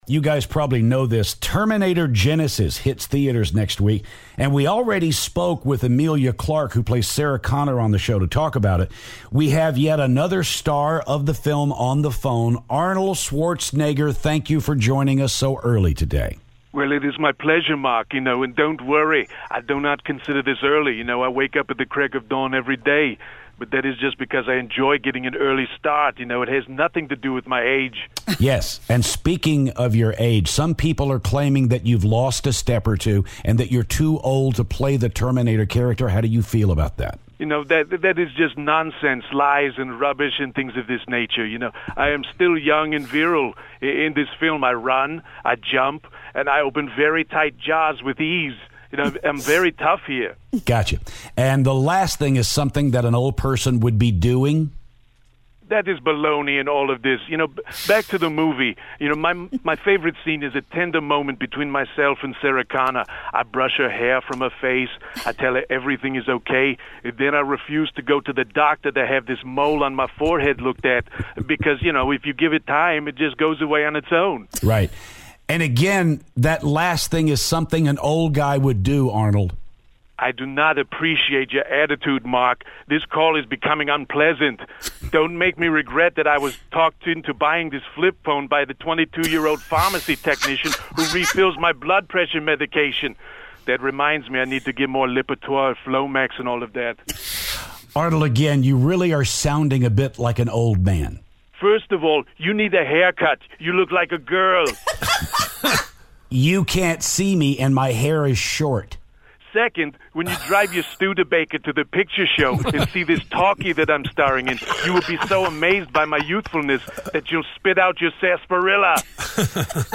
Arnold calls to talk about the new Terminator movie.